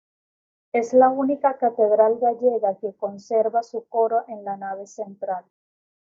ca‧te‧dral
/kateˈdɾal/